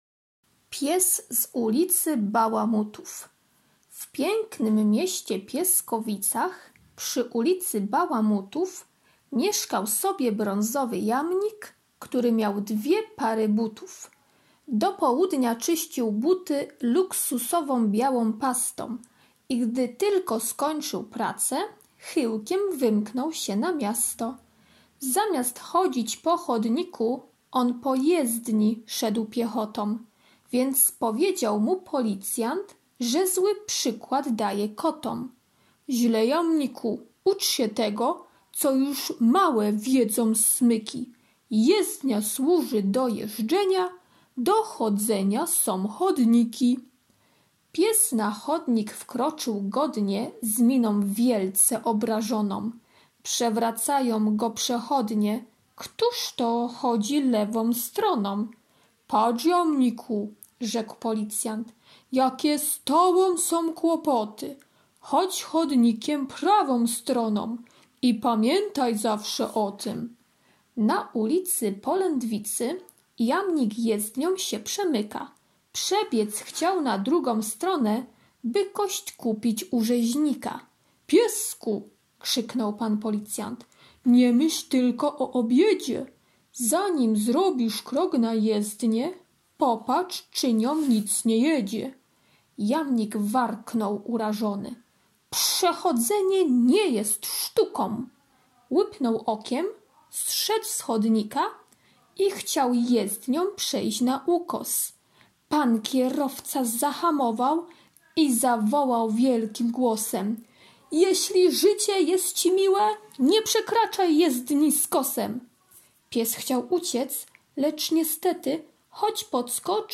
poniedziałek - wiersz "Pies z ulicy Bałamutów" [6.82 MB] poniedziałek - ćw. dla chętnych - kolorowanka [96.00 kB] poniedziałek - ćw. dla chętnych - litera T, t [107.16 kB] ćw. dla chętnych [12.38 kB]